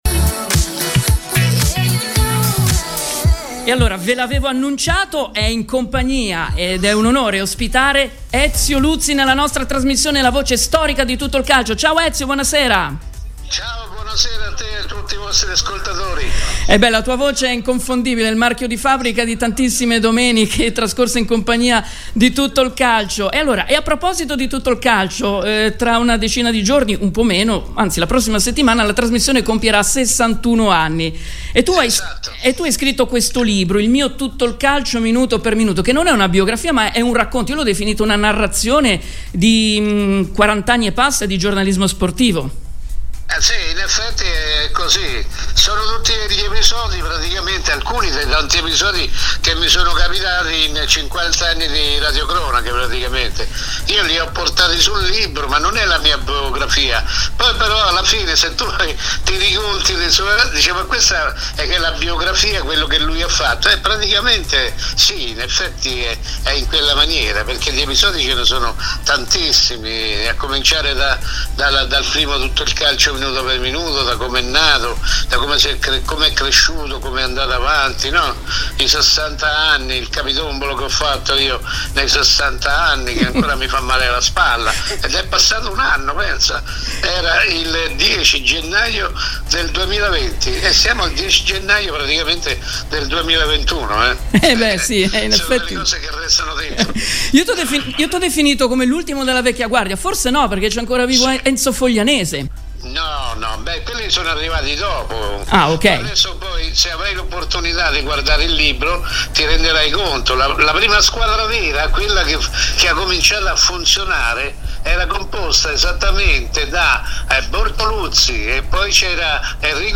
Intervista Ezio Luzzi